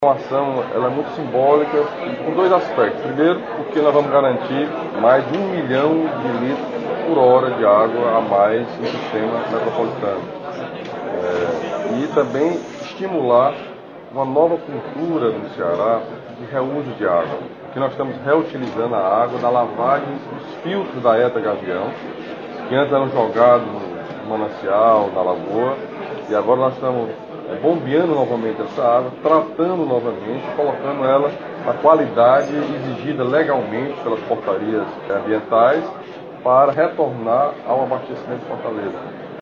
Local: ETA Gavião
Entrevistas:
Governador Camilo Santana